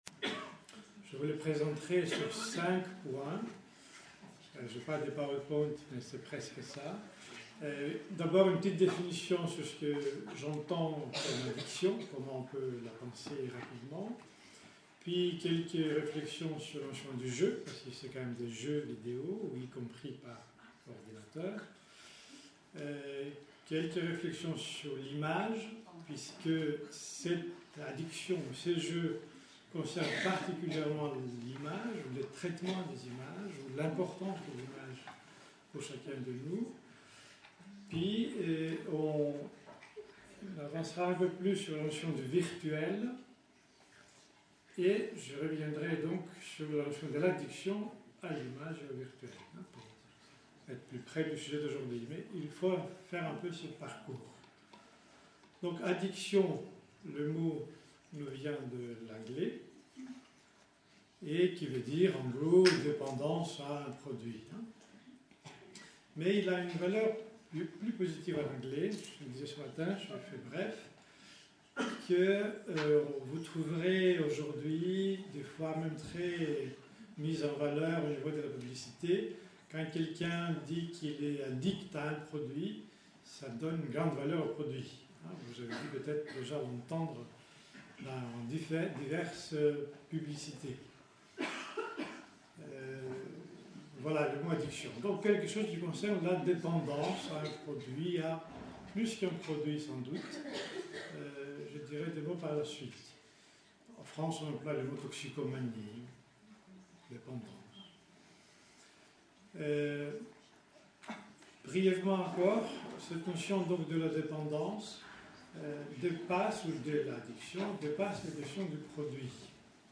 Une conférence de l'UTLS au Lycée Drogues et addictions aux jeux vidéo